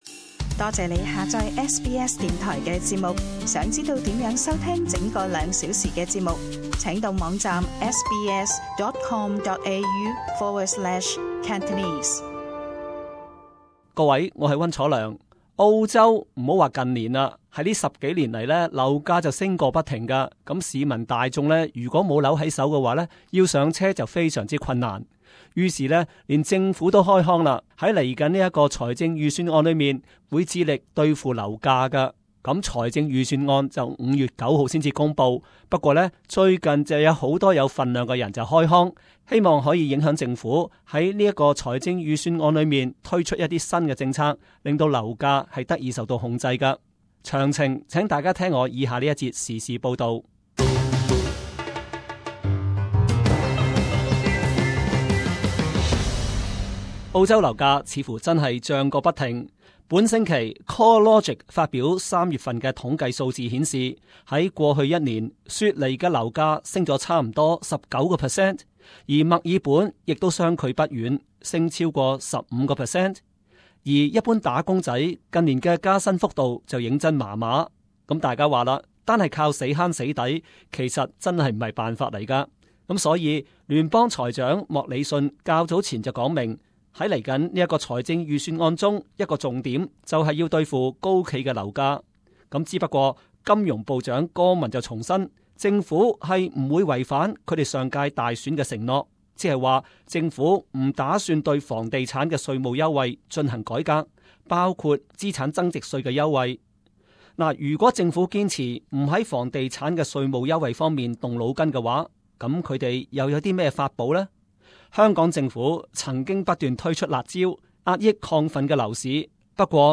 【時事報導】 多位名人要求政府改革稅務優惠對付樓市